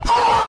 Fortnite O Scream MP3 Download
The Fortnite O Scream sound button is from our meme soundboard library
u84-Fortnite-O-Scream.mp3